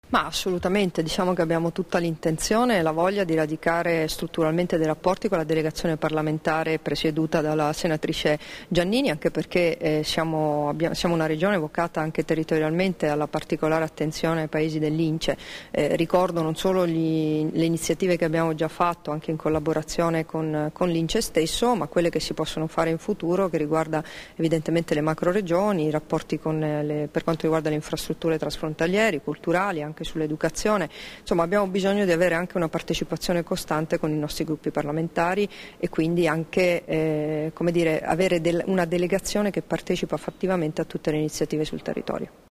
Dichiarazioni di Debora Serracchiani (Formato MP3) [685KB]
rilasciate a margine dell'incontro con la senatrice Stefania Giannini, neoeletta presidente della Delegazione parlamentare italiana presso l'Assemblea parlamentare dell'InCE-Iniziativa Centro Europea, a Trieste il 10 gennaio 2014